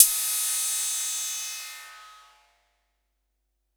Tr8 Cymbal 04.wav